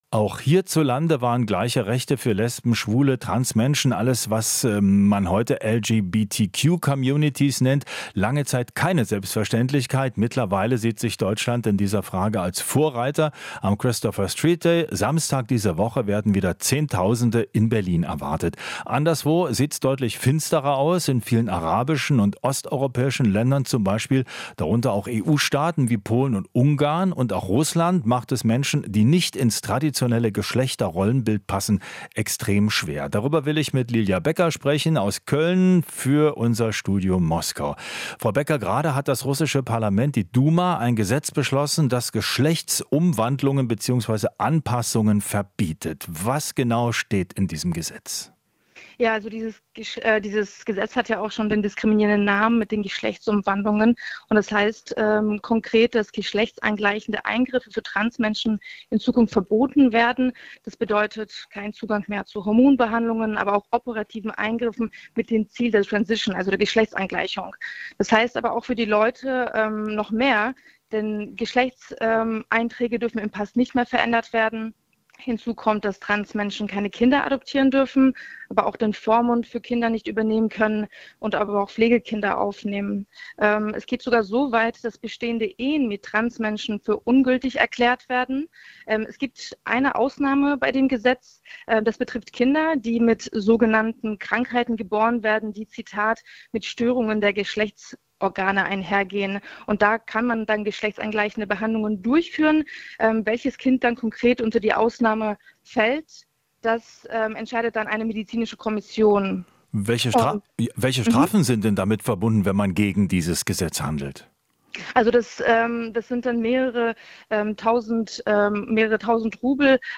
Interview - Einschränkungen für LGBTQ-Community in Russland